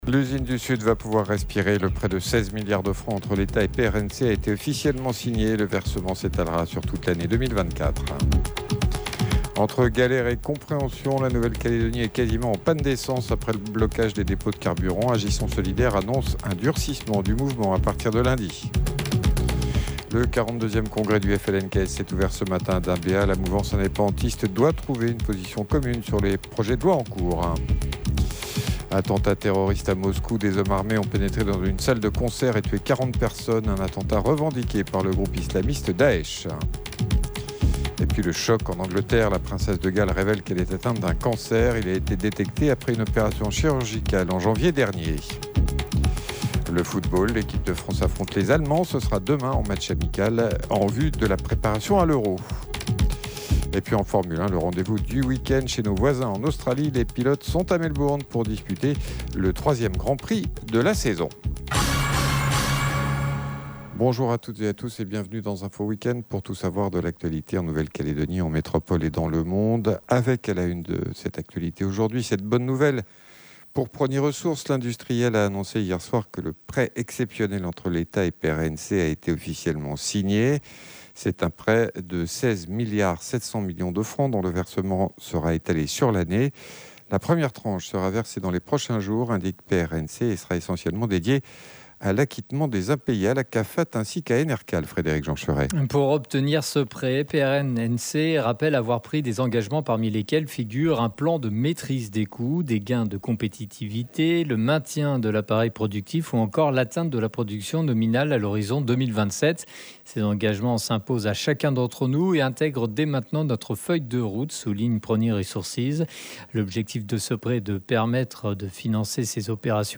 JOURNAL : INFO WEEK END SAMEDI MIDI